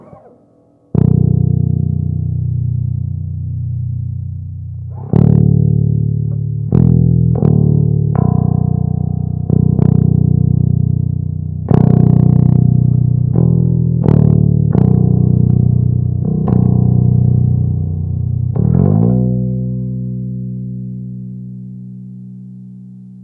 Udělal jsem i první nahrávku, takže zvuk můžete posoudit sami - oba poťáky naplno, rovnou do vzukovky, bez úprav.